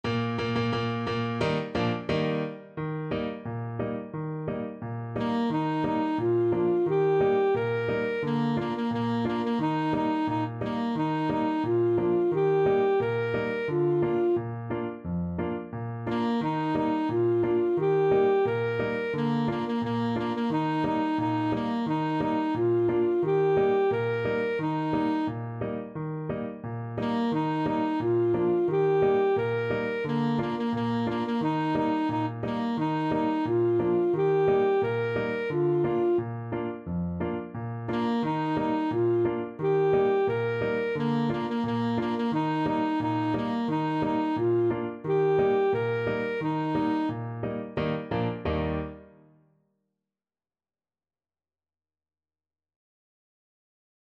Alto Saxophone version
Alto Saxophone
2/4 (View more 2/4 Music)
Bb4-Bb5
Steady march =c.88
Traditional (View more Traditional Saxophone Music)
Swiss